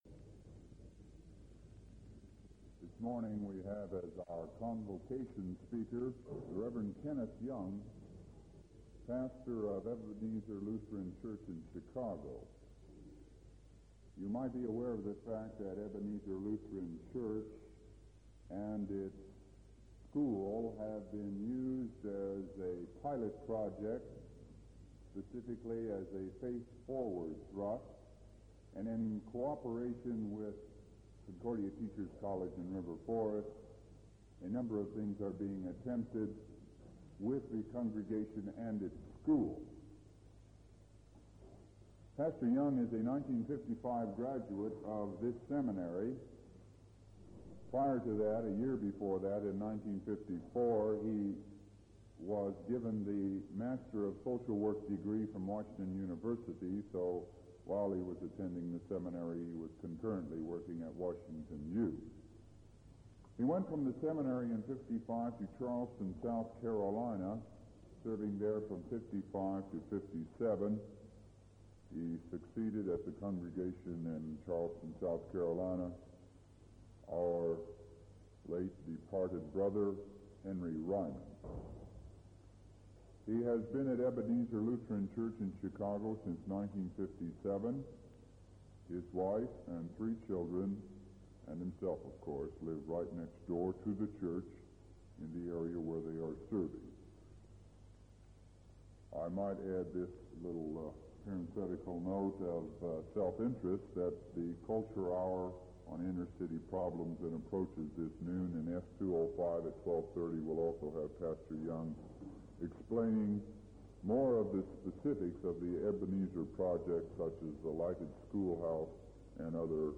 Convocation held at Concordia Seminary, St. Louis, February 3, 1965.